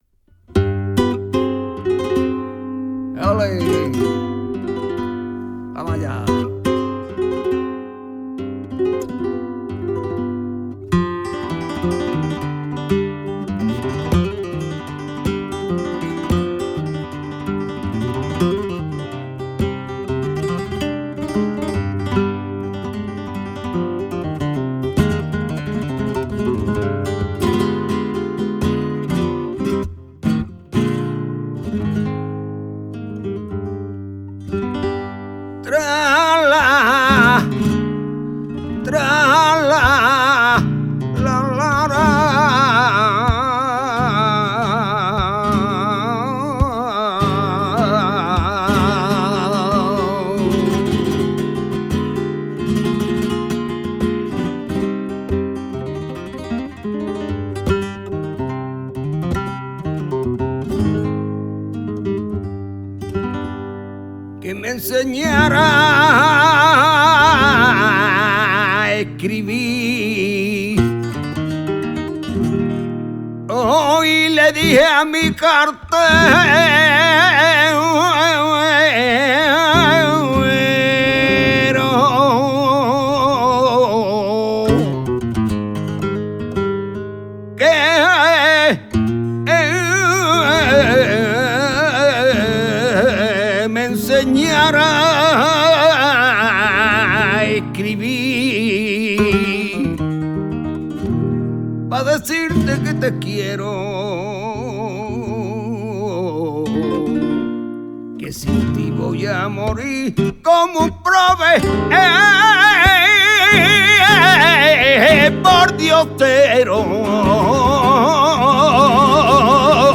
guitare
fandangos